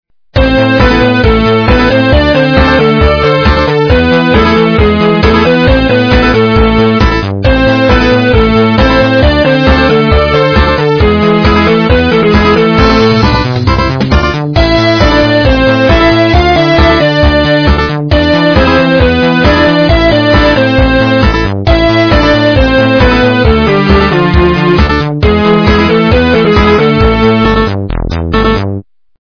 западная эстрада
полифоническую мелодию